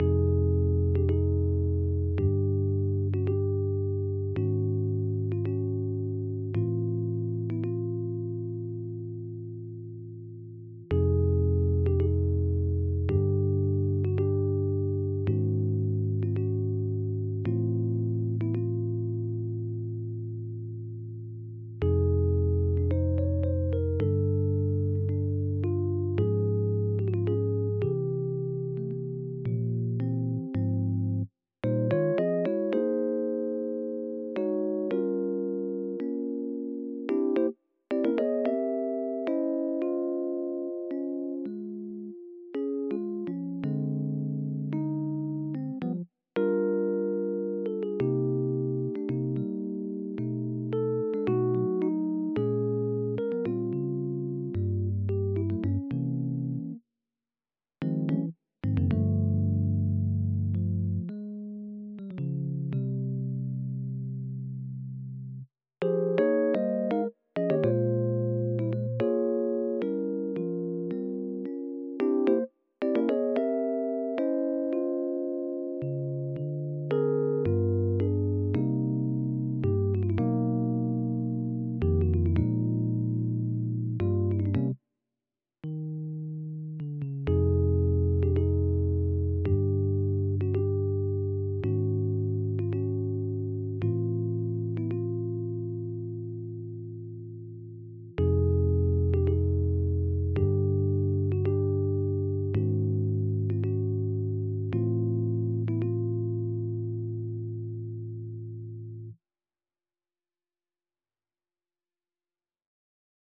• Short Pieces for Saxophone Quartet
(I rendered it here with a mellower instrument to take the edge off.)